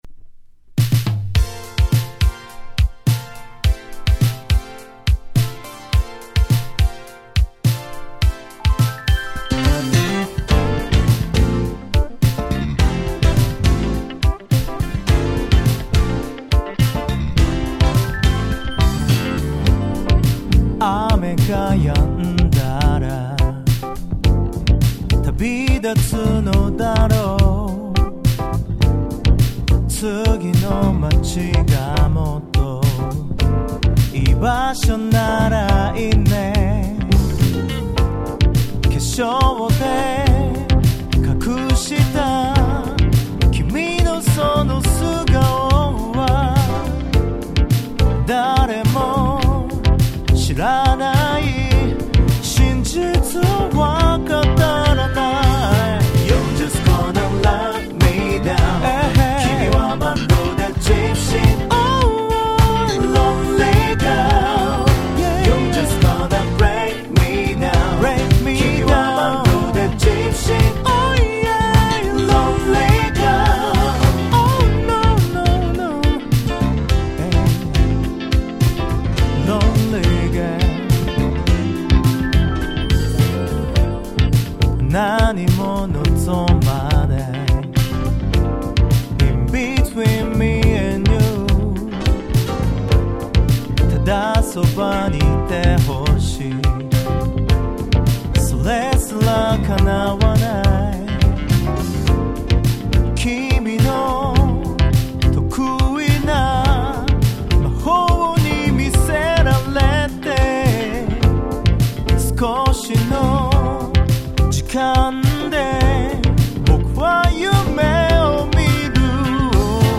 19' Nice Japanese R&B !!
最高にMellowでGroovy !!